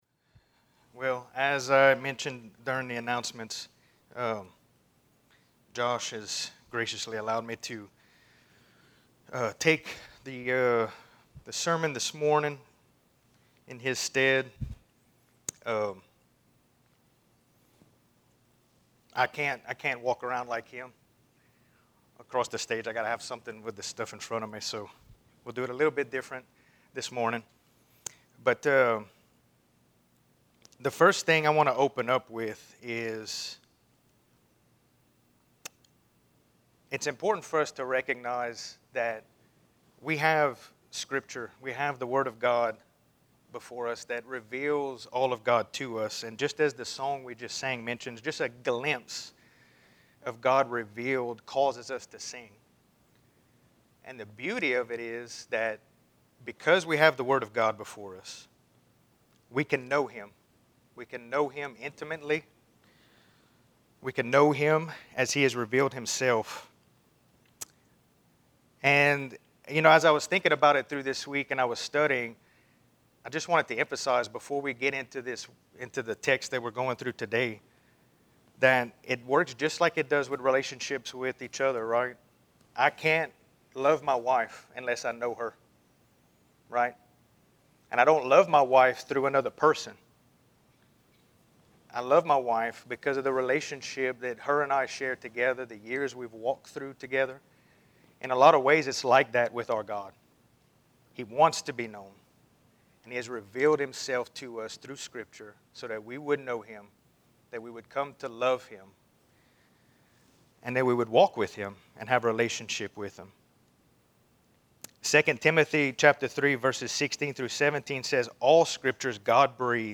A message from the series "Luke." Jesus pleads in the Garden